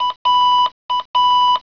BEEPSLO1.WAV